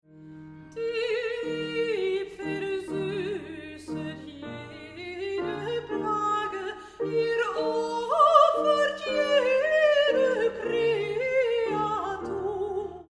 sopraan voor klassieke en lichte muziek